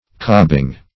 Cobbing - definition of Cobbing - synonyms, pronunciation, spelling from Free Dictionary
Cobbing \Cob"bing\, a.